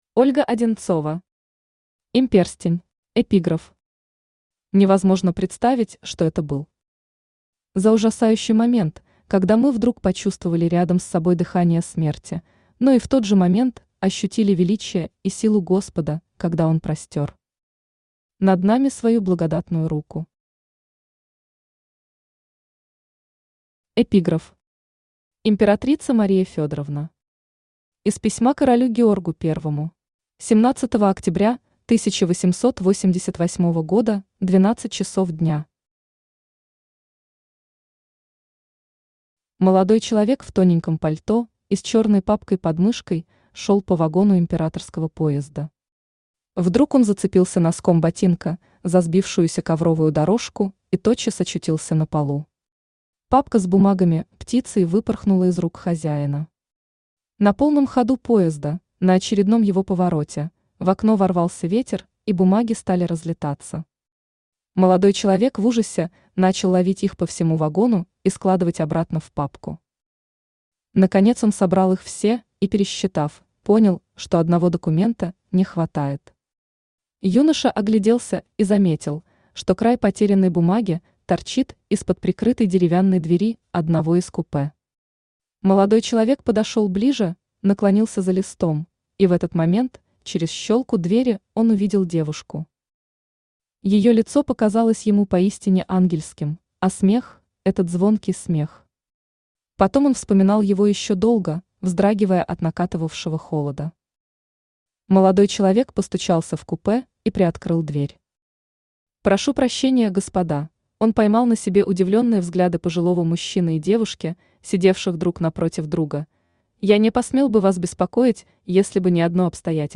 Аудиокнига ИМПЕРстень | Библиотека аудиокниг
Aудиокнига ИМПЕРстень Автор Ольга Одинцова Читает аудиокнигу Авточтец ЛитРес.